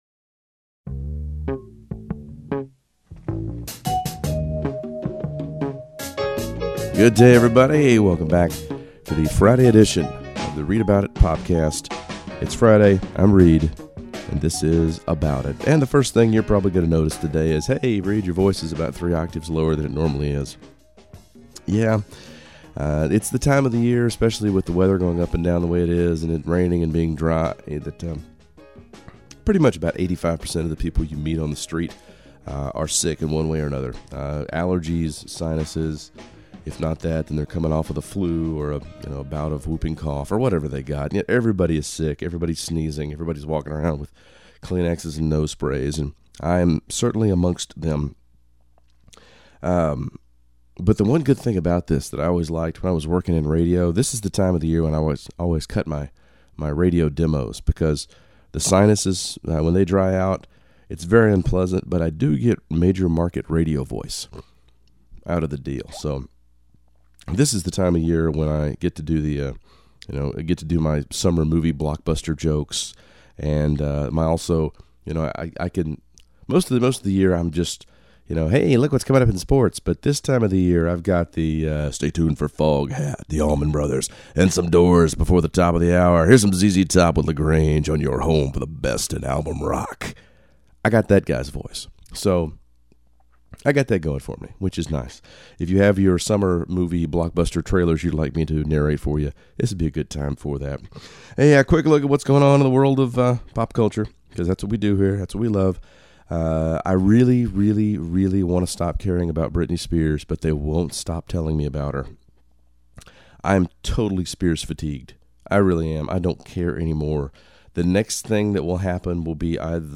The new Popcast is up, six minutes of pop culture discussions delivered in a voice ridiculously deep with allergies. This week, we talk writers, Britney, Nip/Tuck, and the end of the TV Fall Rollout.